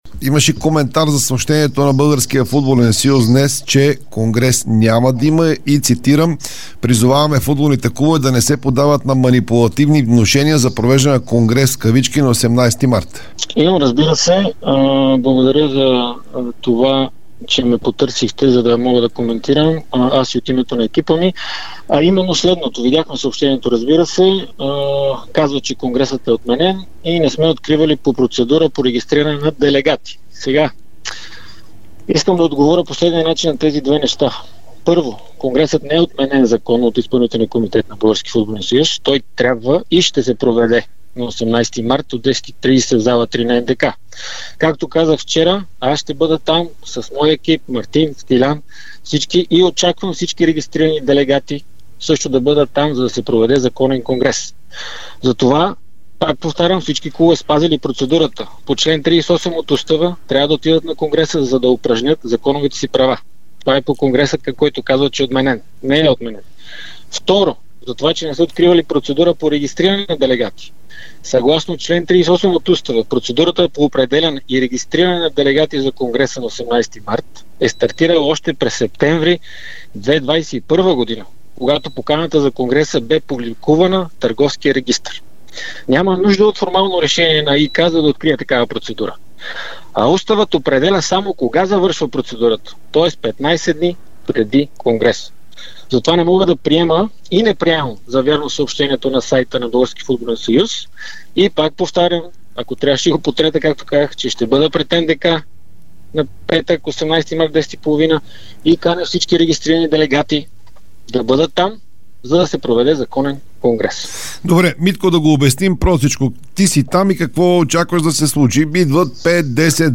Кандидатът за президент на Българския футболен съюз Димитър Бербатов даде ексклузивно интервю за dsport и Дарик радио.